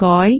speech
cantonese
syllable
pronunciation
goi2.wav